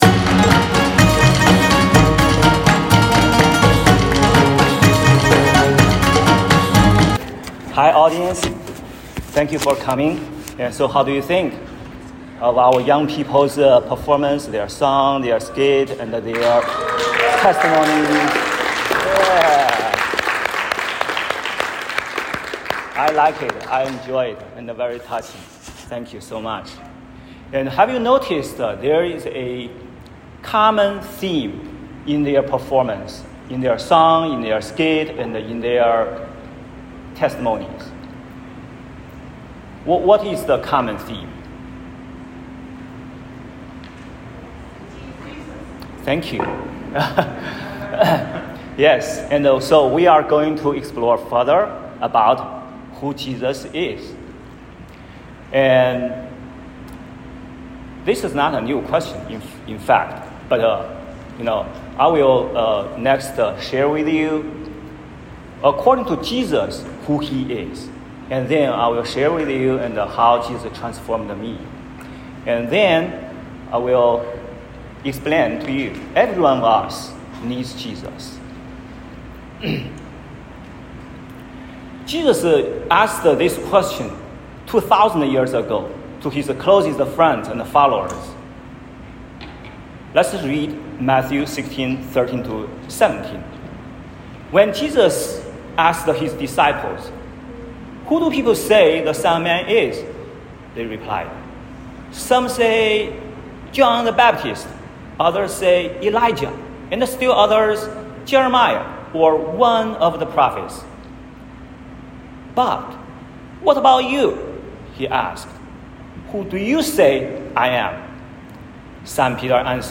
2025-06-09 YIMC 布道会